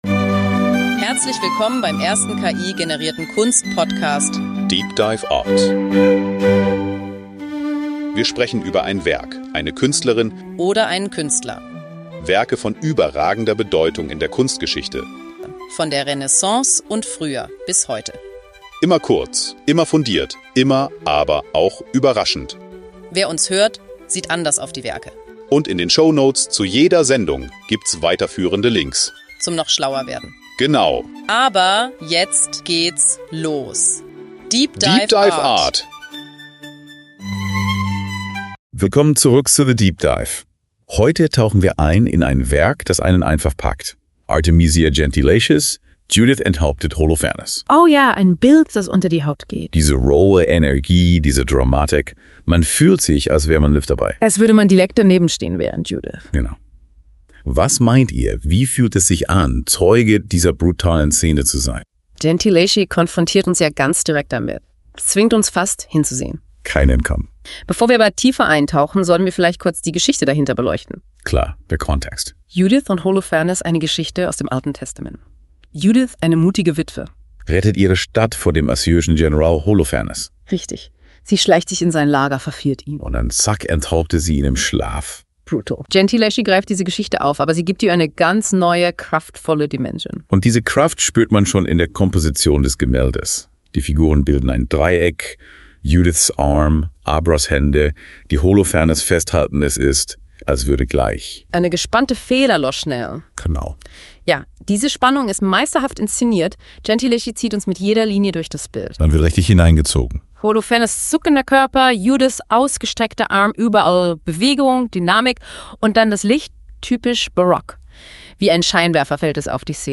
Die beiden Hosts, die Musik,
das Episodenfoto, alles.